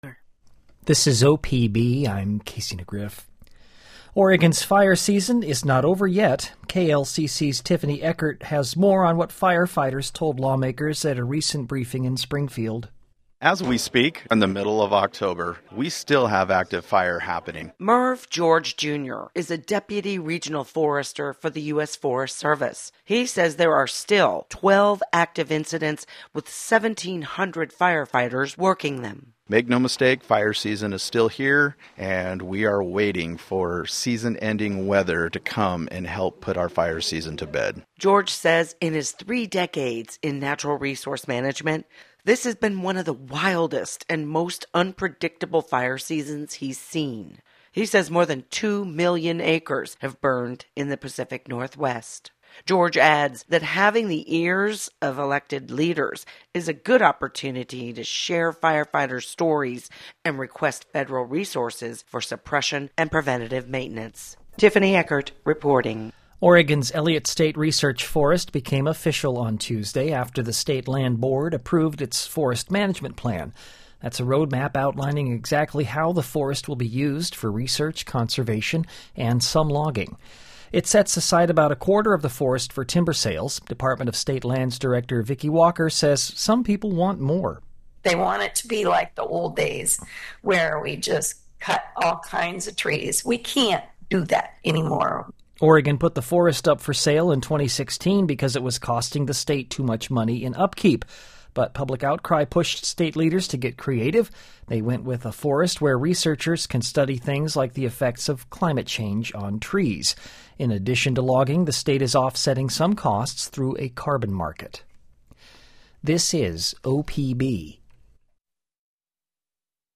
opbnewscast.mp3